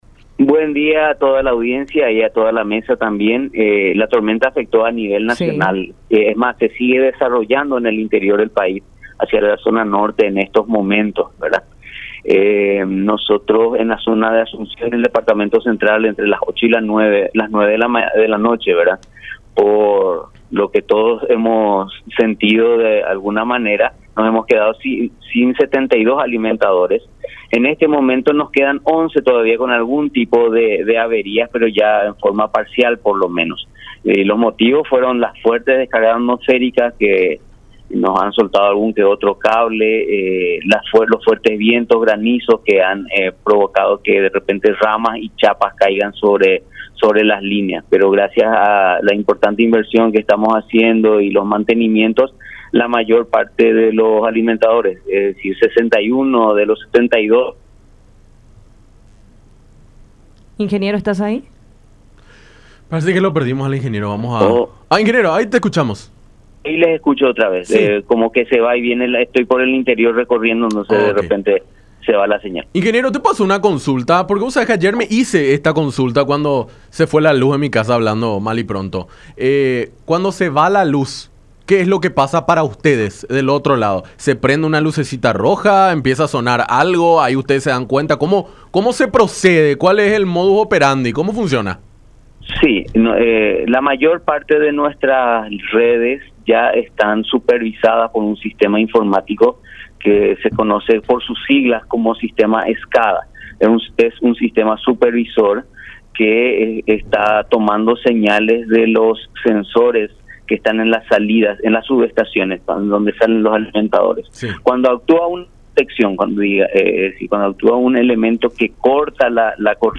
en conversación con Enfoque 800 a través de La Unión